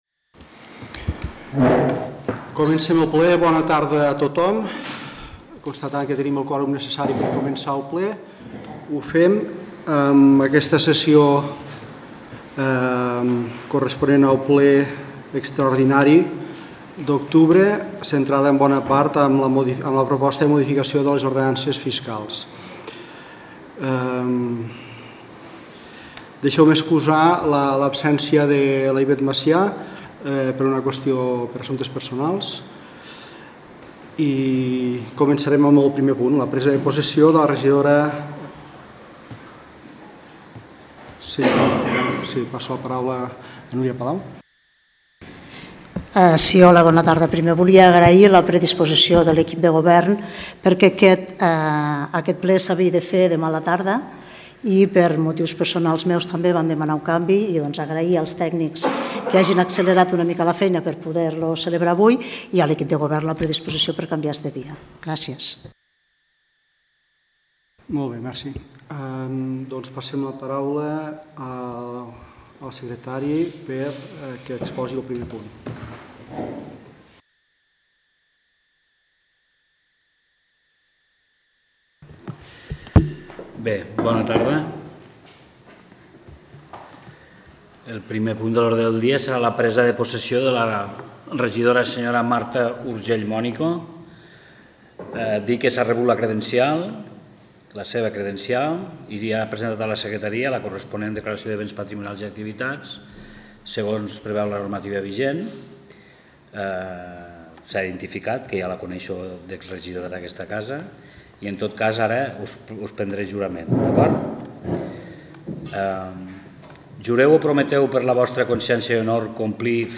El passat dimarts, 29 d'octubre, l'Ajuntament de les Borges va celebrar una sessió plenària extraordinària que va començar, a les 19 hores, a la sala de plens i es va poder seguir en directe a través de la nostra emissora i de Les Borges TV.